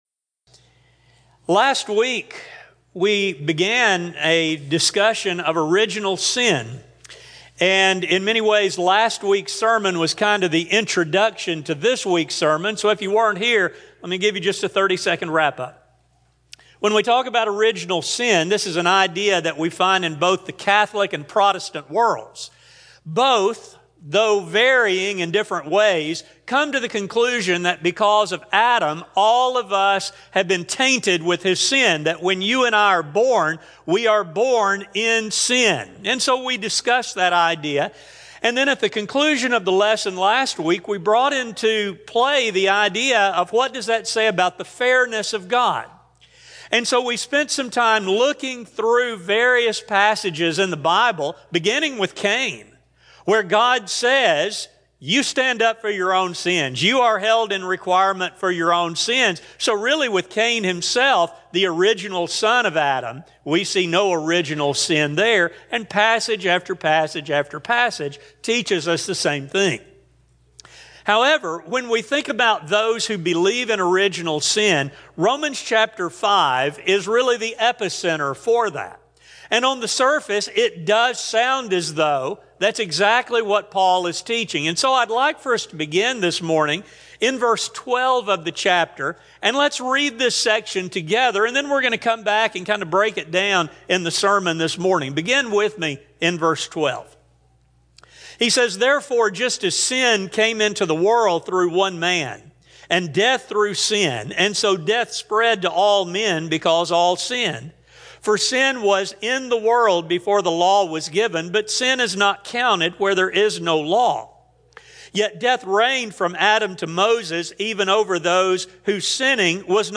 General Service: Sun AM Type: Sermon Speaker